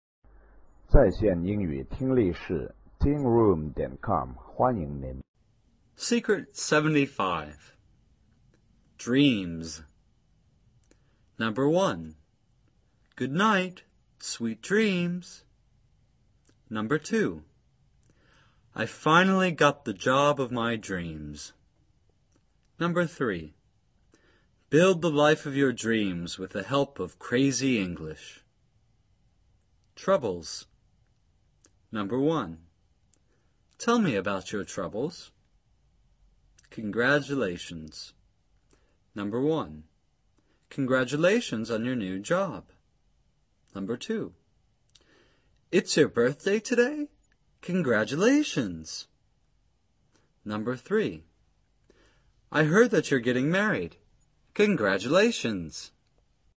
李阳美语发音秘诀之秘诀75:词尾浊辅音[d]除外的浊辅音+[s]的辅音连缀在口语中的读音 听力文件下载—在线英语听力室